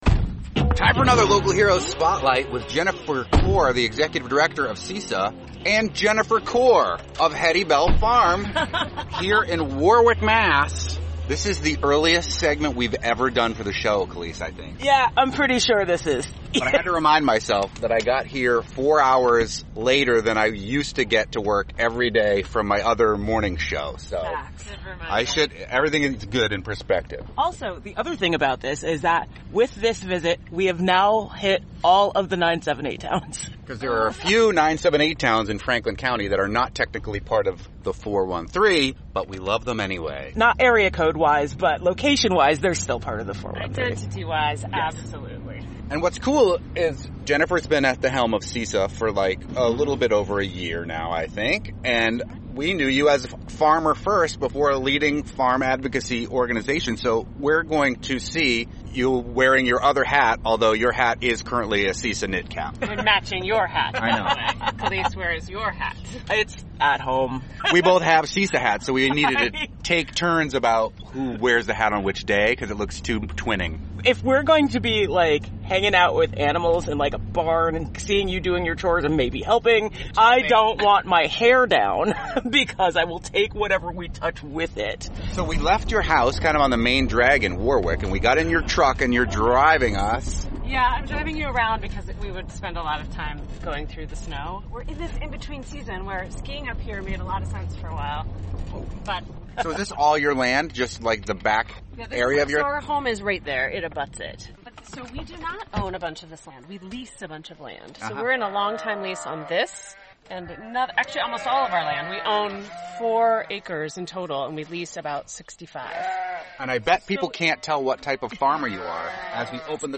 Listen to a slice of the life of a farmer, as they visit newborn lambs, feed grain, carry water, and clean up.
By opening her farm for this interview, listeners can enjoy a glimpse into a day in the life of our local farmers.